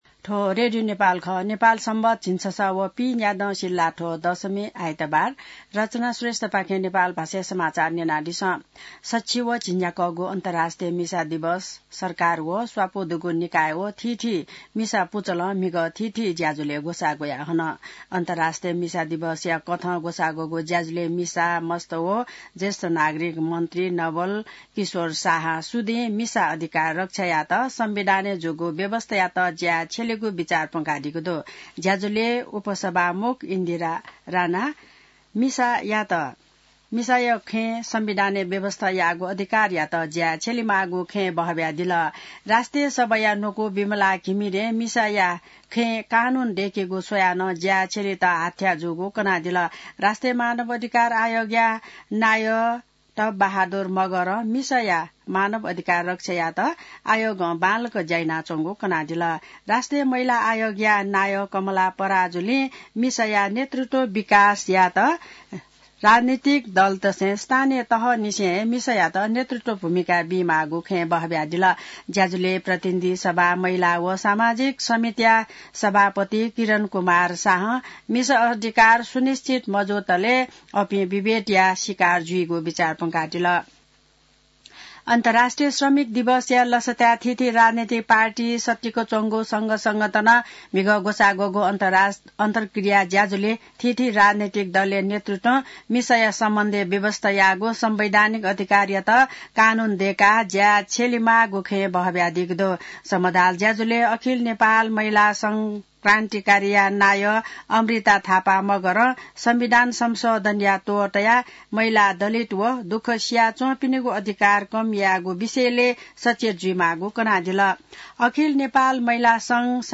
नेपाल भाषामा समाचार : २६ फागुन , २०८१